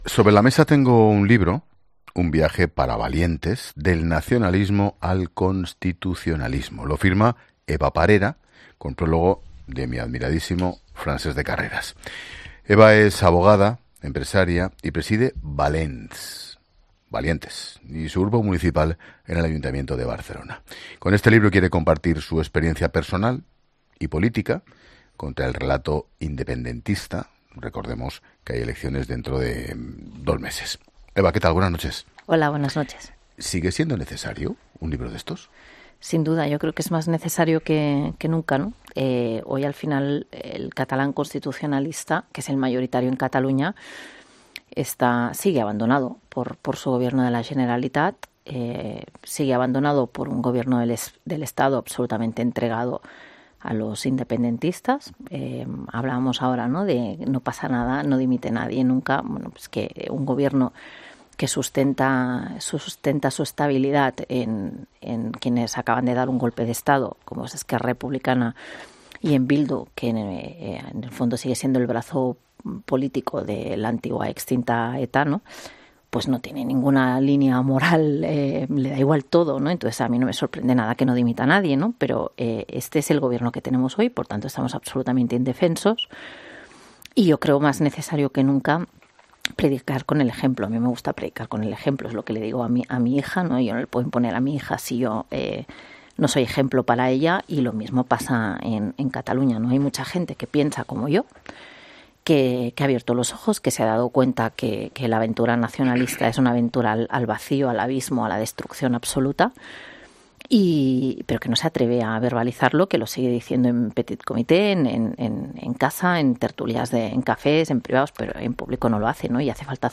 La concejala portavoz del Grupo Municipal Valents en el Ayuntamiento de Barcelona ha pasado por los micrófonos de COPE para hablar de su obra y comentar la situación pasada, presente y futura de Cataluña.